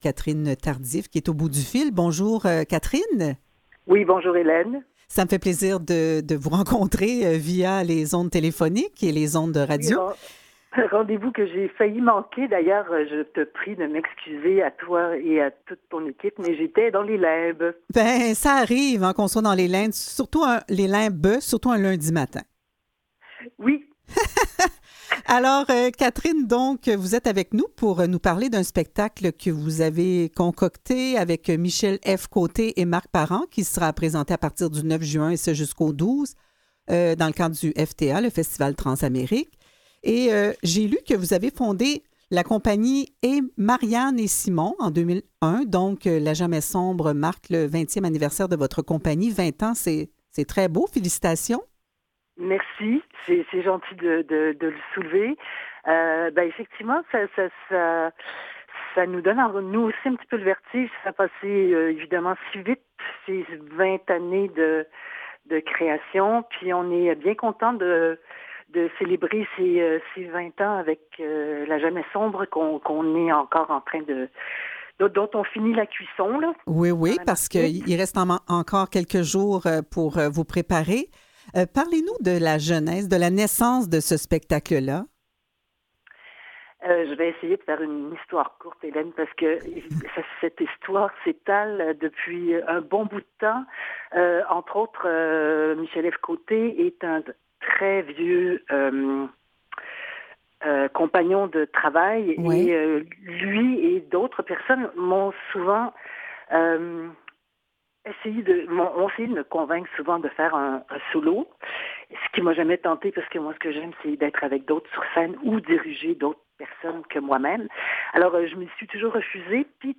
Revue de presse et entrevues du 31 mai 2021